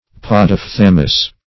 Search Result for " podophthalmous" : The Collaborative International Dictionary of English v.0.48: Podophthalmic \Pod`oph*thal"mic\, Podophthalmous \Pod`oph*thal"mous\, a. [Podo- + Gr.
podophthalmous.mp3